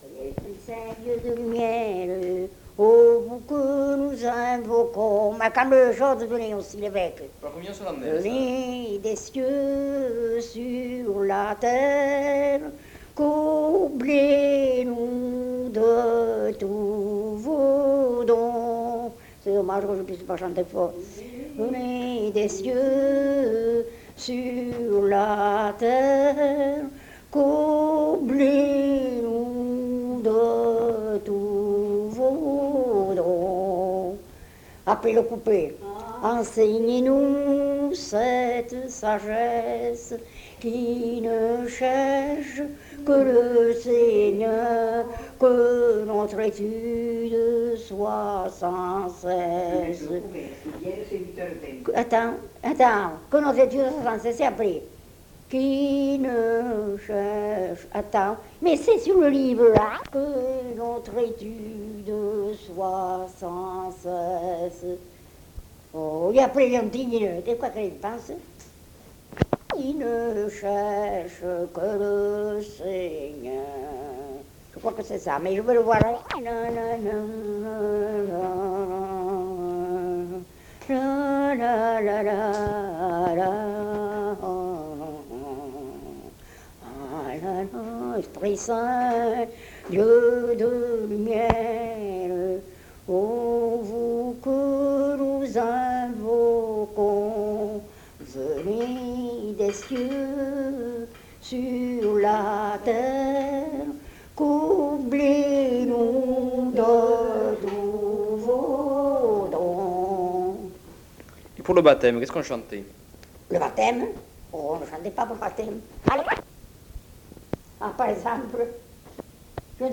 Lieu : Mas-Cabardès
Genre : chant
Effectif : 1
Type de voix : voix de femme
Production du son : chanté
Contextualisation de l'item : pour la communion solennelle.